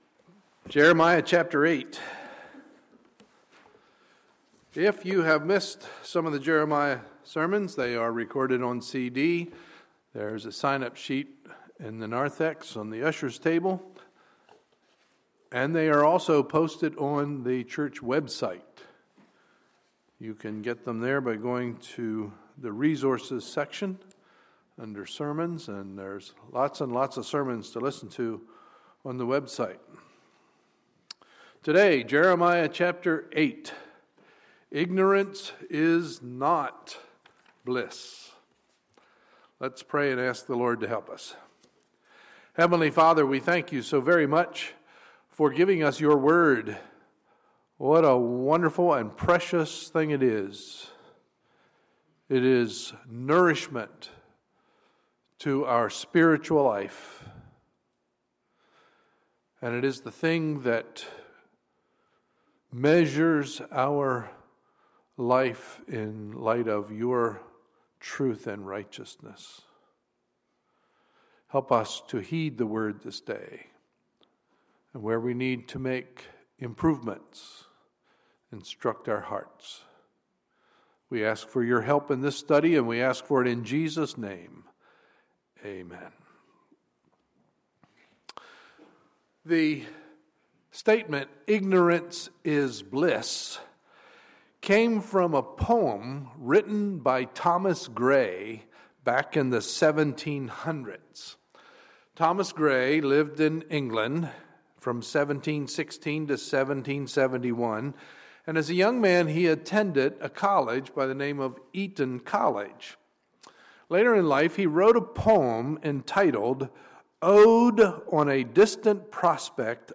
Sunday, March 8, 2015 – Sunday Morning Service